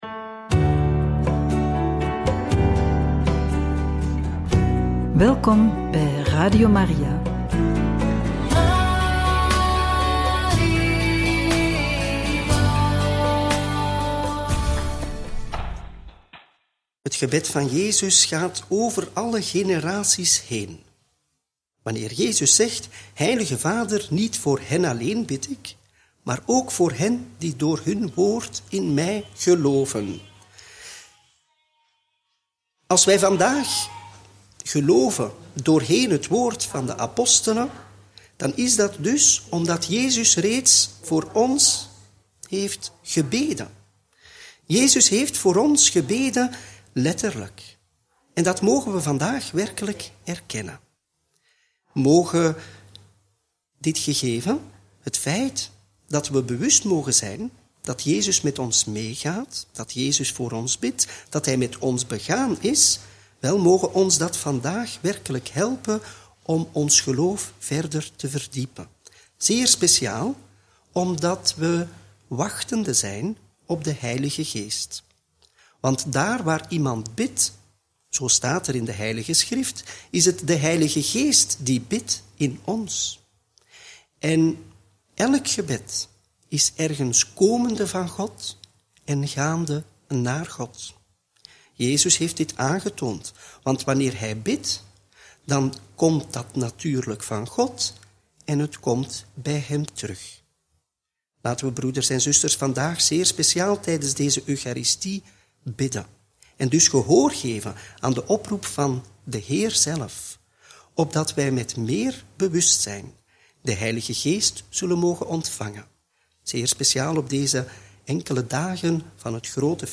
Homilie bij het Evangelie van donderdag 5 juni 2025 – Joh. 17, 20-26